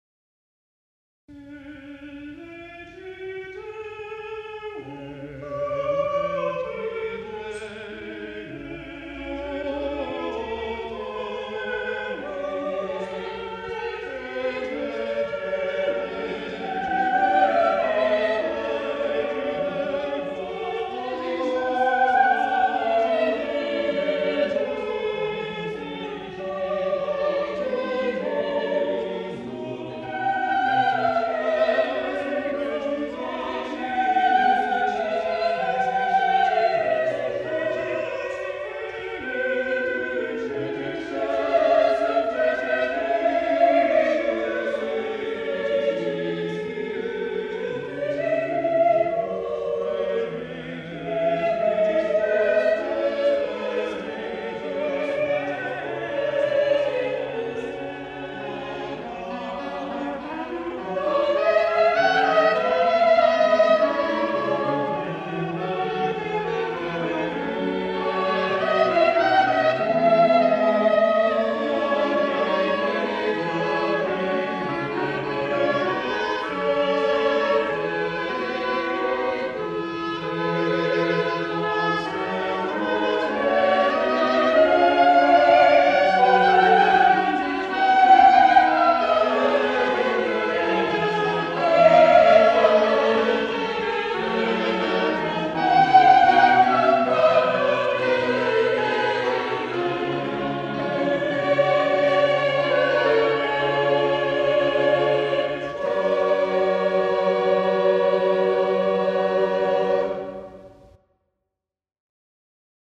Many of the pieces are lively spiritual villancicos written for Christmas and Corpus Christi, which were especially requested by the authorities and much appreciated by the populace.